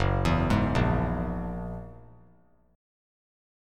F#9 Chord
Listen to F#9 strummed